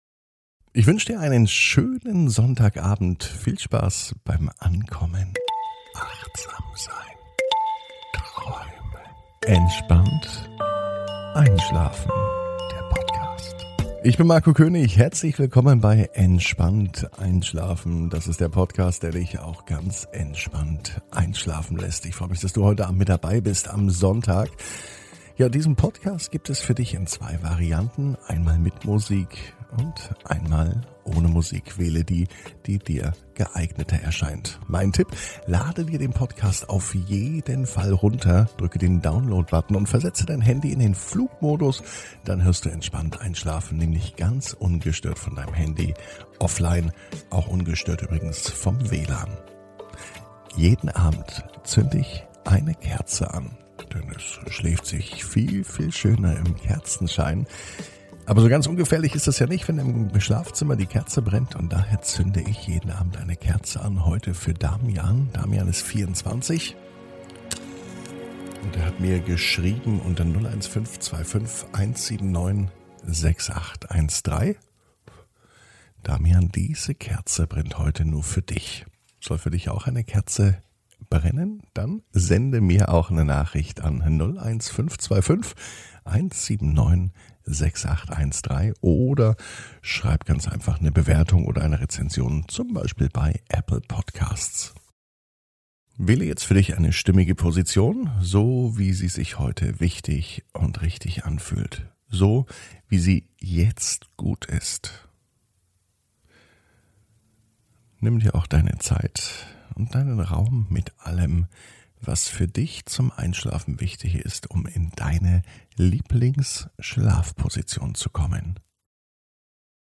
(ohne Musik) Entspannt einschlafen am Sonntag, 20.06.21 ~ Entspannt einschlafen - Meditation & Achtsamkeit für die Nacht Podcast